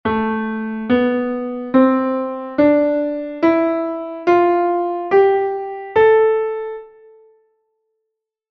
Modos eclesiásticos ou gregorianos
plagal, finalis re, repercusio fa